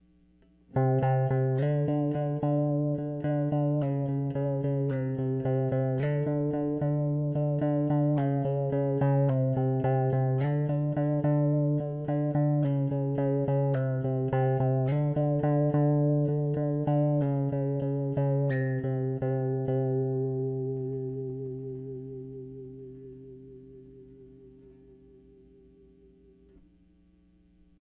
电基调
描述：Fender电贝司上的Bassline.
Tag: 低音 电动 挡泥板 线